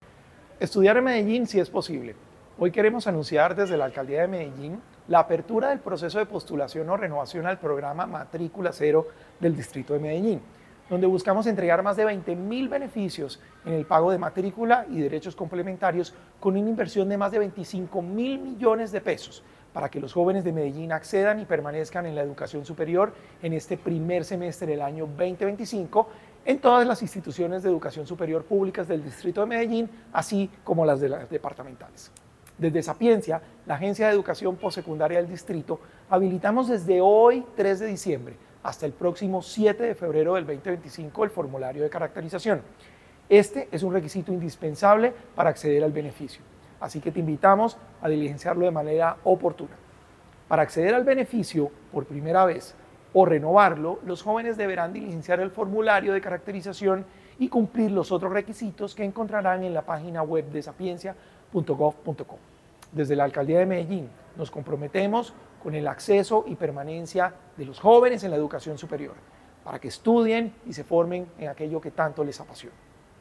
Palabras de Salomón Cruz Zirene, director de Sapiencia